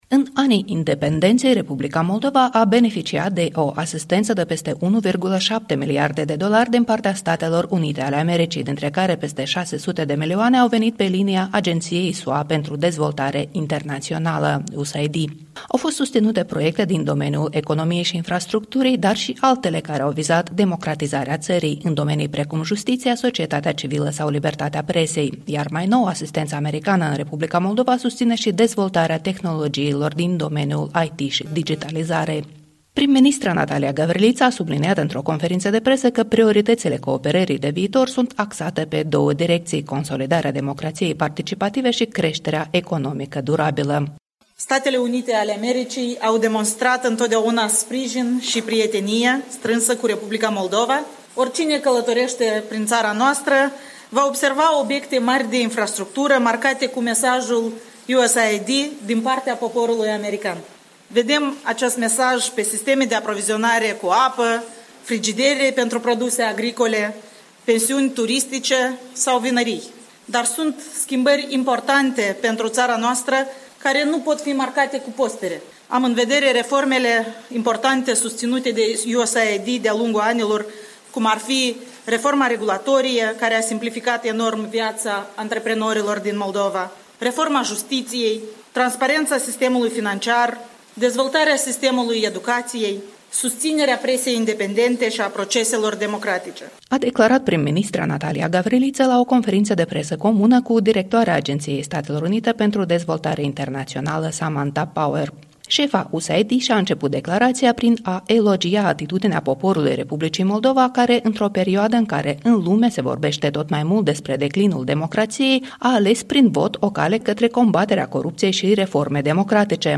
La o conferință de presă comună cu prim-ministra Natalia Gavriliță, Samantha Power a spus că Statele Unite vor oferi noi ajutoare.
Iată ce a declarat Samantha Power, în traducerea oferită de Guvern.